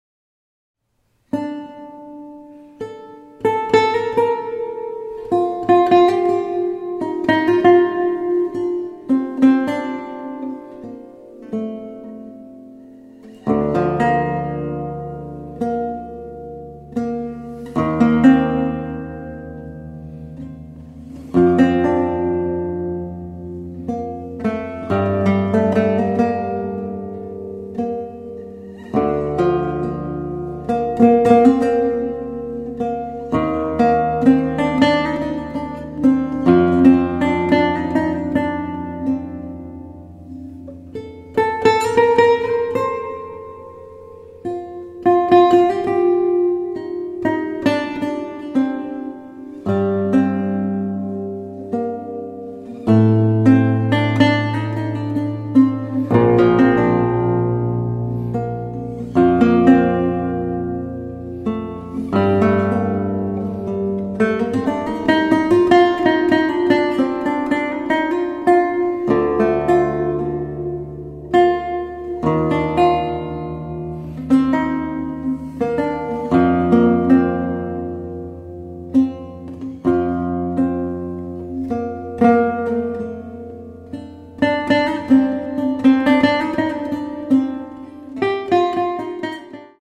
baroque lute